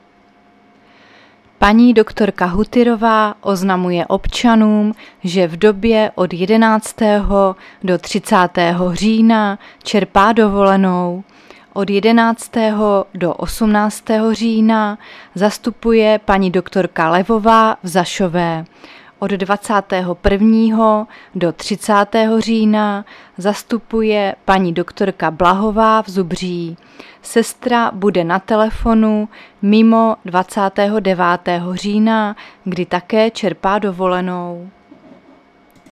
Záznam hlášení místního rozhlasu 8.10.2024
Zařazení: Rozhlas